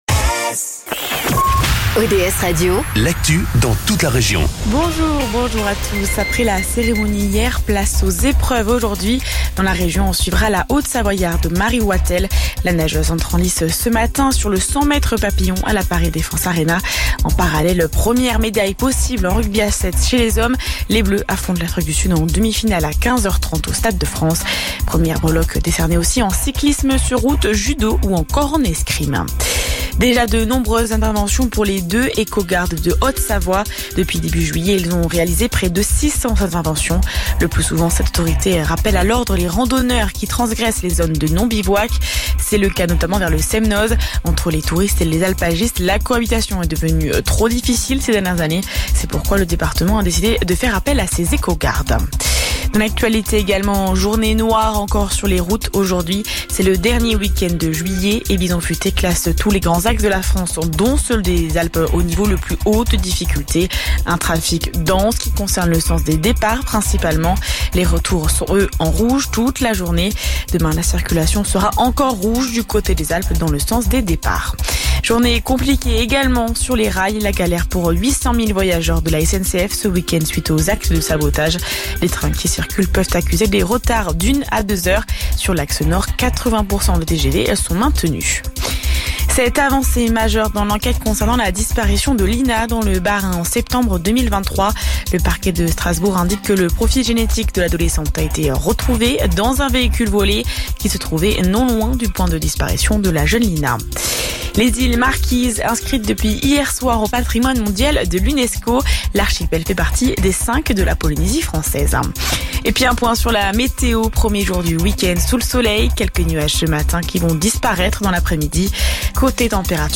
Le Flash Info, le journal d'ODS radio